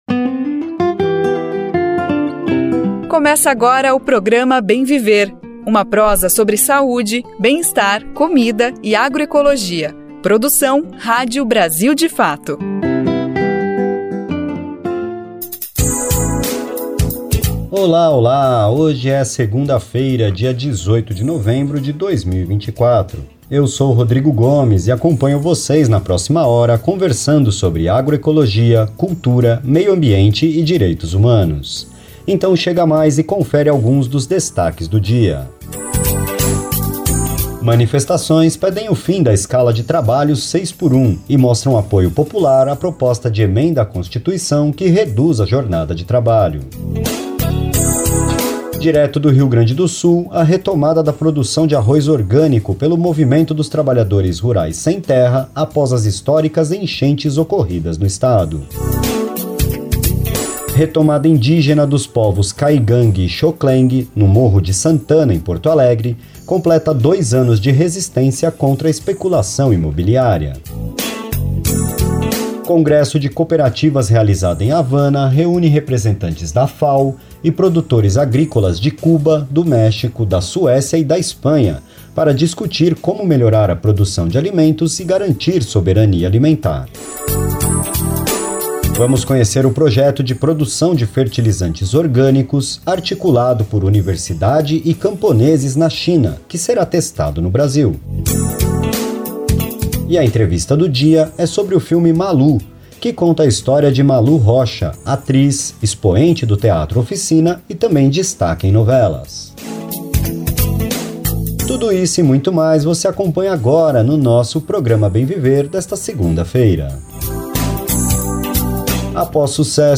Inspirado na vida da atriz de teatro Malu Rocha, o filme Malu chegou ao Brasil sendo multi-premiado no Festival Internacional do Rio, colecionando 4 prêmios, entre eles o de melhor atriz para Yara de Novaes, que protagoniza o longa. Em entrevista ao programa Bem Viver desta segunda-feira (18), Novaes compartilhou a emoção e os desafios […]